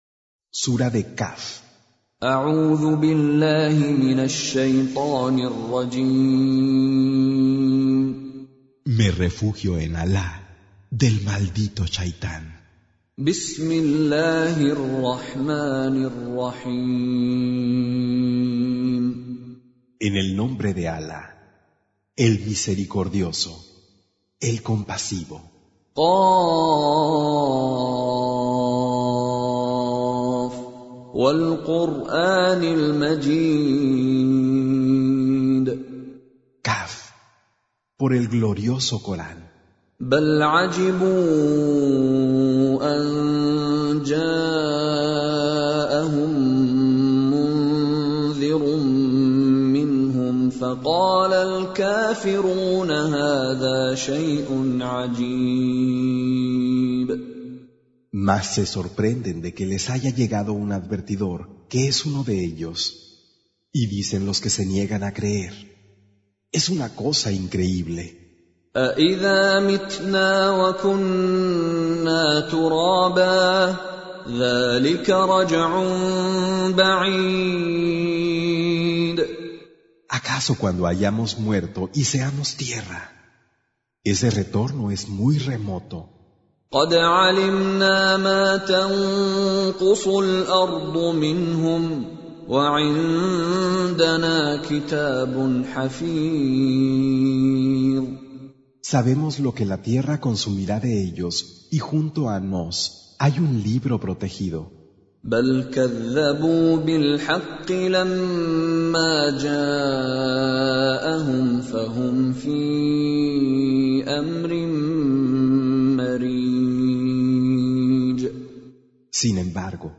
Surah Sequence تتابع السورة Download Surah حمّل السورة Reciting Mutarjamah Translation Audio for 50.